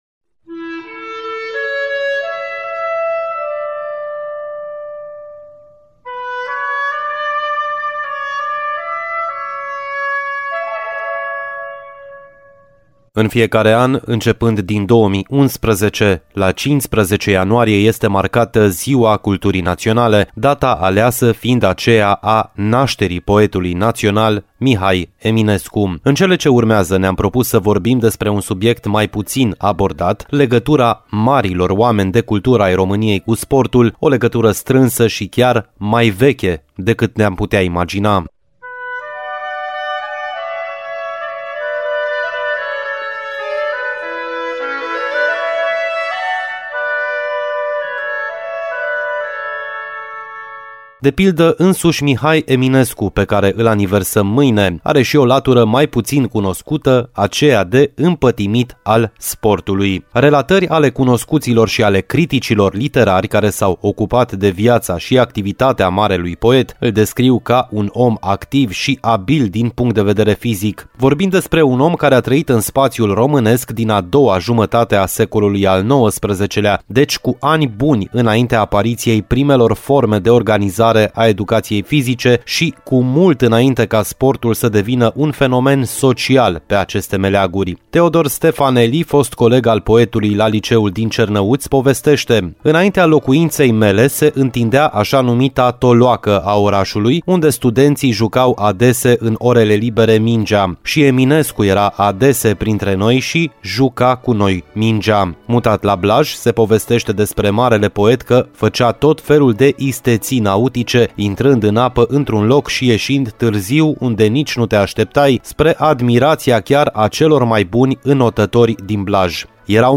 Reportajul despre legătura marilor oameni de litere ai României cu sportul a fost difuzat în ediția de astăzi a emisiunii Arena Radio.